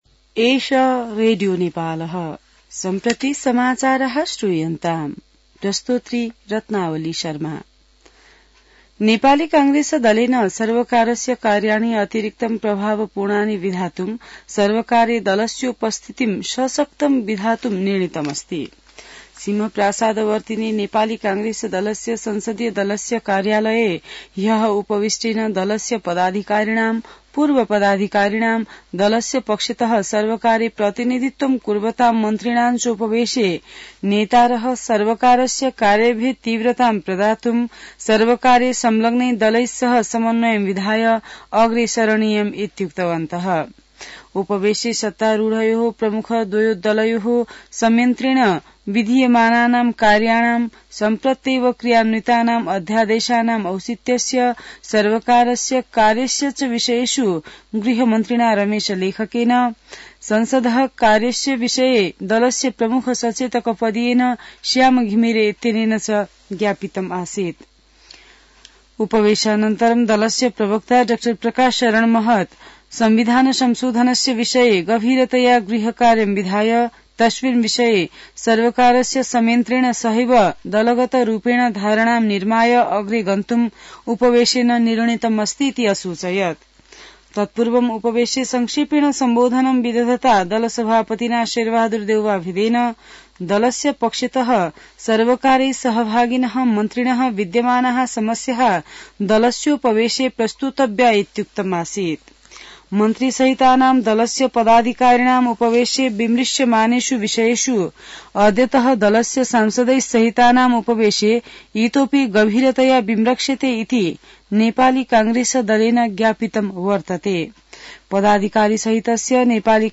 संस्कृत समाचार : १४ माघ , २०८१